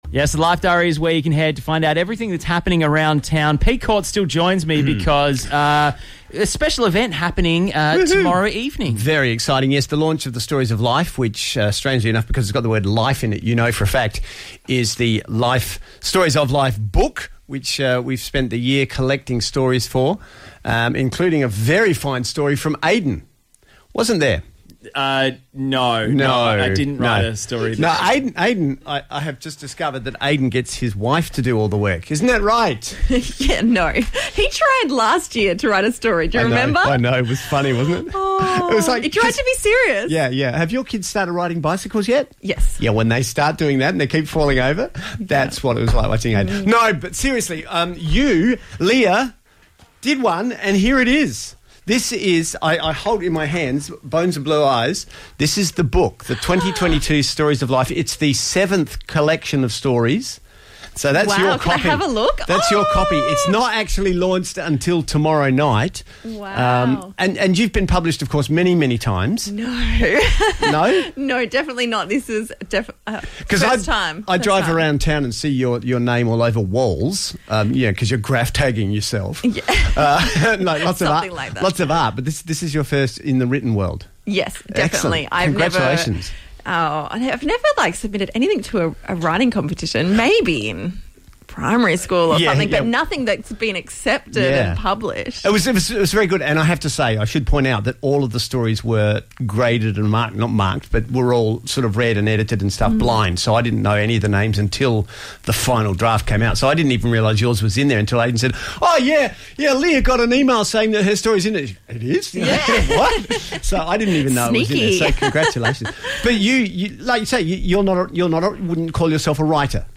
At the 1079 Life studios earlier this afternoon